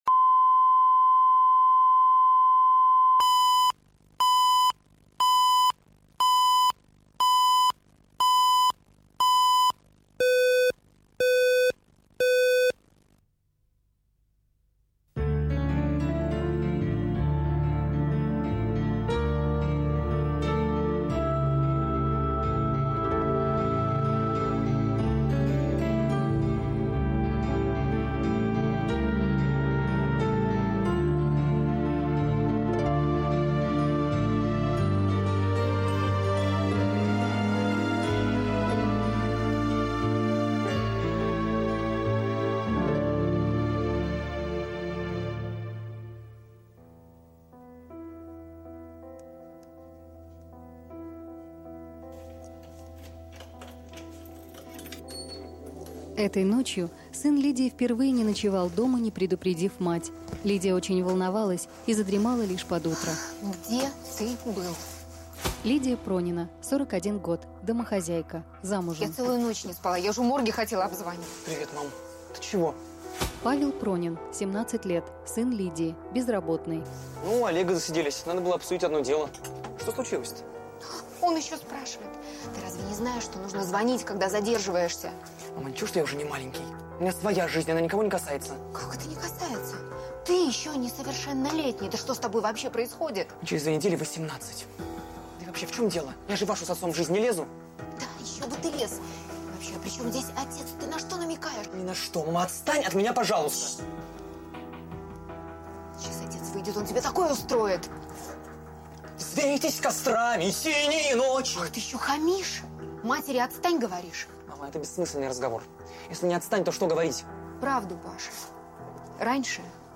Аудиокнига История одного развода | Библиотека аудиокниг